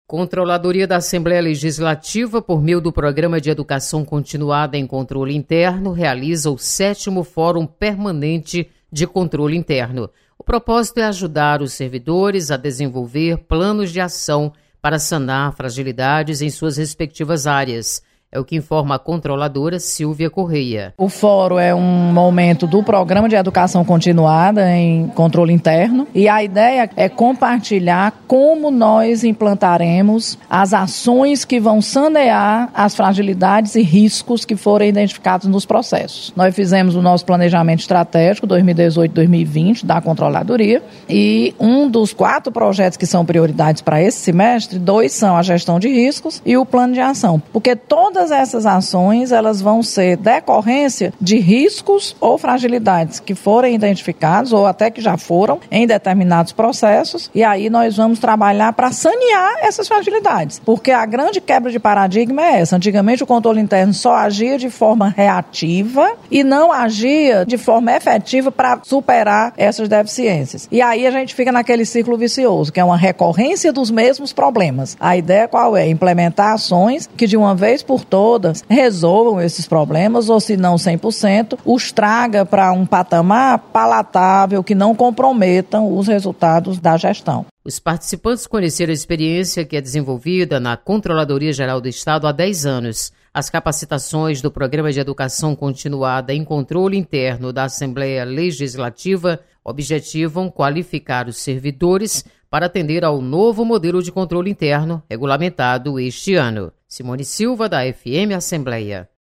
Controladoria realiza Programa de Educação Continuada em Controladoria. Repórter